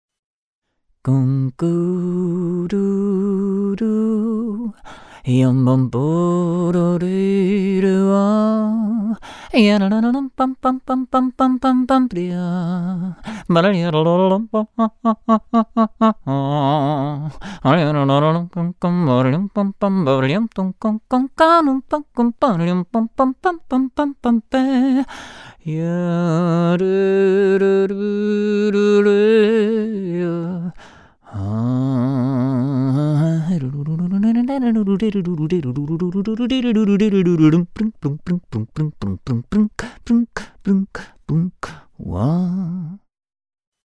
Gepfiffener Jazz von Mozart Hits
Die besten Klassiker als Gassenhauer im Kurzformat.